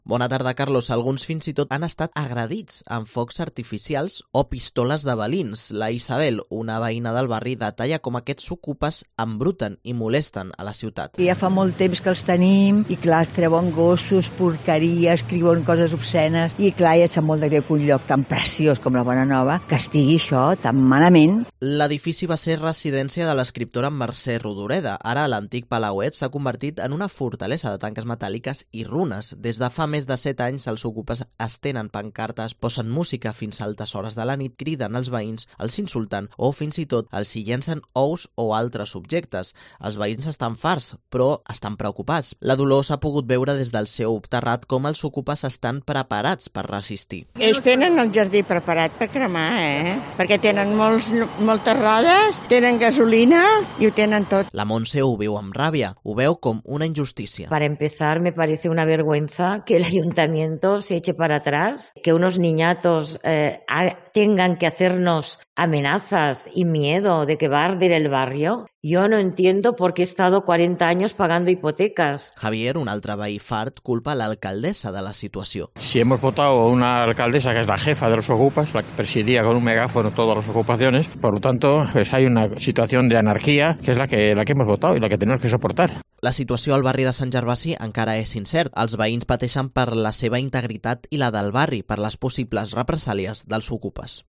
crónica de los vecinos del barrio de Sant Gervasi (Barcelona) hartos de los okupas